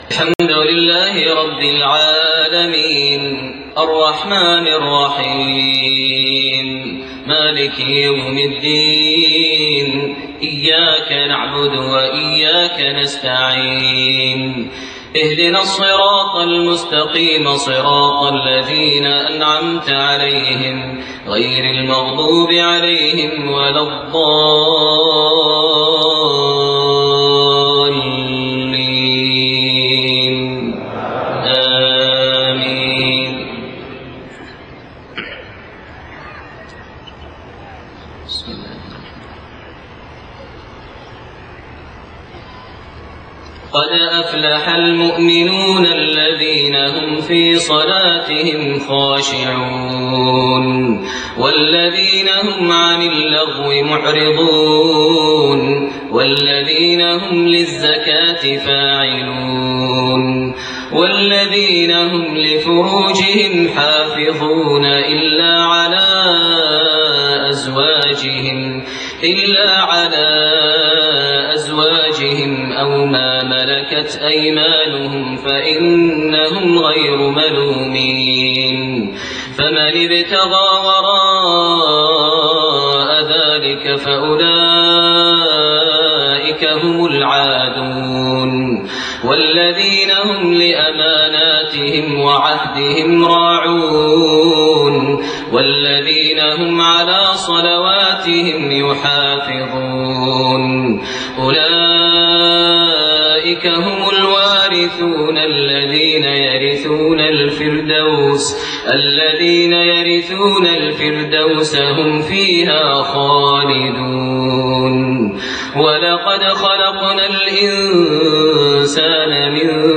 Isha prayer surah Al-Muminoon > 1429 H > Prayers - Maher Almuaiqly Recitations